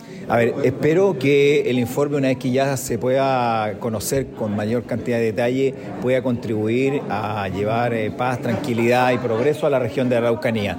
El diputado Jorge Rathgeb, presidente regional de Renovación Nacional, espera que la entrega del informe permita avanzar por la paz y tranquilidad en la región.